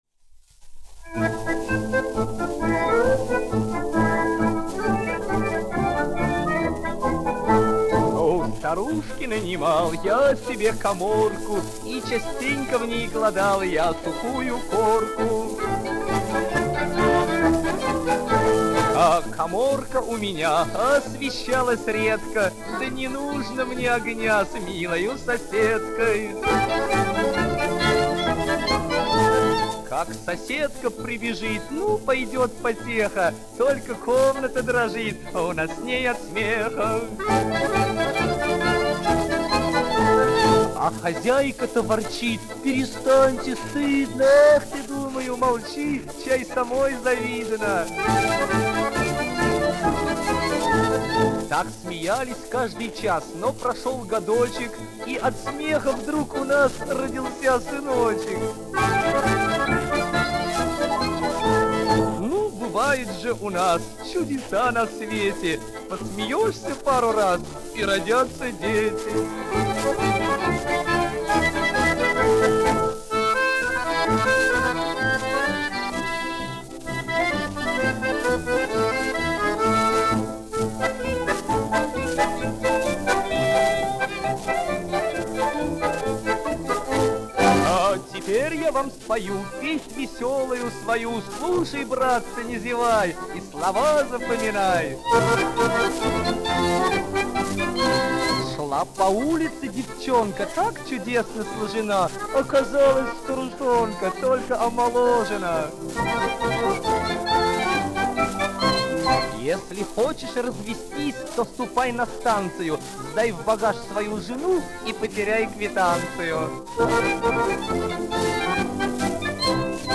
1 skpl. : analogs, 78 apgr/min, mono ; 25 cm
Častuškas
Krievu tautasdziesmas
Skaņuplate